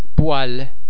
oi oê oe oy [Wa][ typically French sound]
The French [wah ] sound is very much like the [w] sound in English words why, wide, wet.
wa_poele.mp3